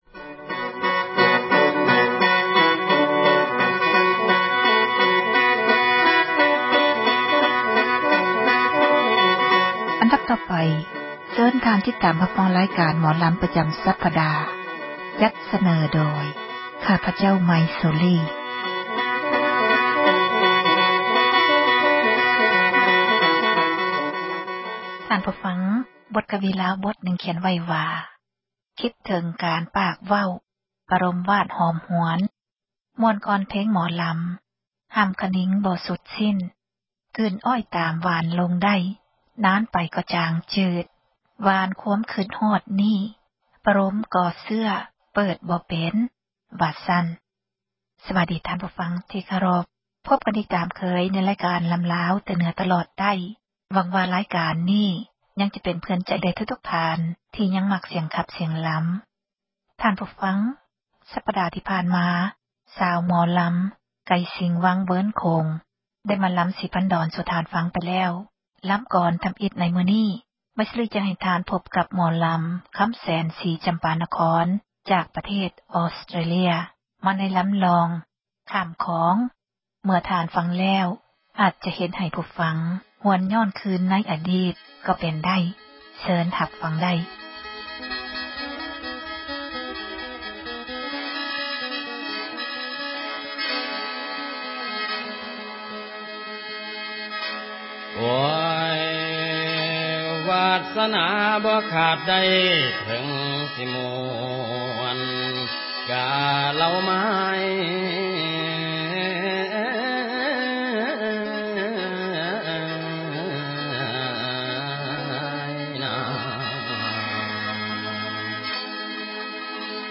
ຣາຍການ ໝໍລຳລາວ ປະຈຳ ສັປດາ ຈັດສເນີ ທ່ານ ໂດຍ